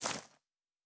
Coin and Purse 08.wav